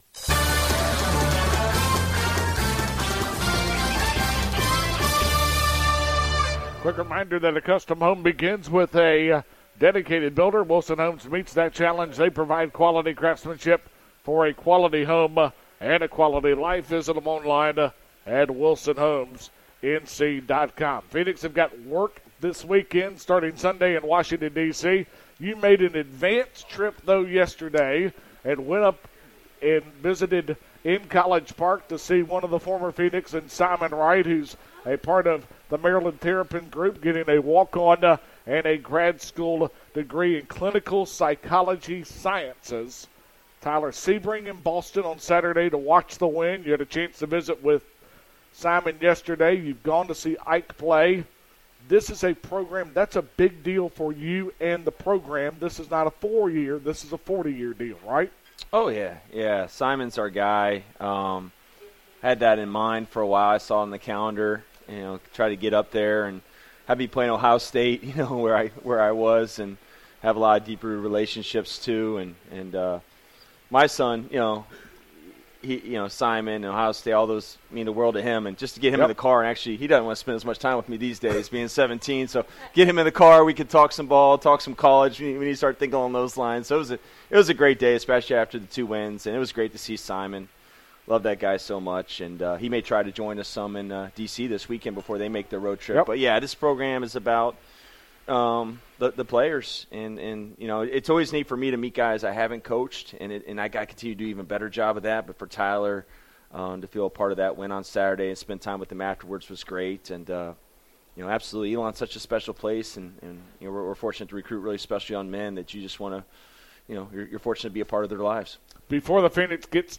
Below are audio files from the final broadcast of the year.